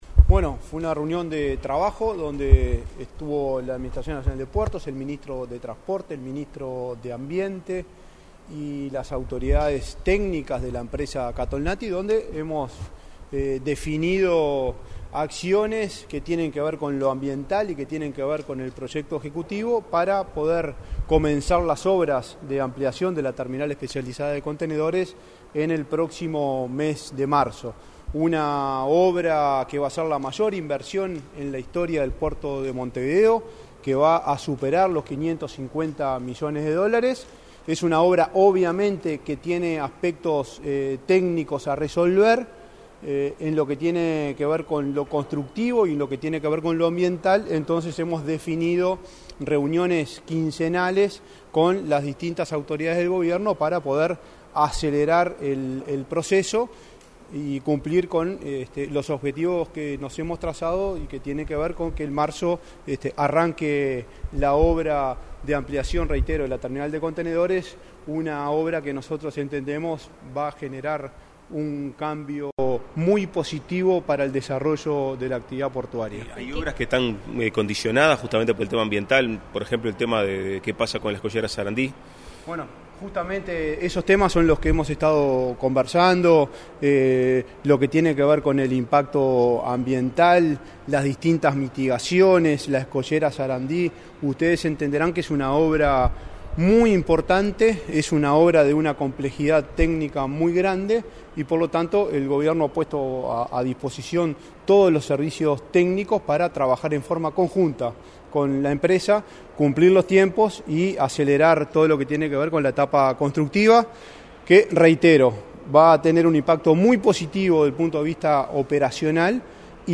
Declaraciones a la prensa del presidente de la ANP, Juan Curbelo
Declaraciones a la prensa del presidente de la ANP, Juan Curbelo 18/10/2022 Compartir Facebook X Copiar enlace WhatsApp LinkedIn El presidente de la Administración Nacional de Puertos (ANP), Juan Curbelo, se reunió con el secretario de la Presidencia, Álvaro Delgado. Tras el encuentro, este 18 de octubre, Curbelo realizó declaraciones a la prensa.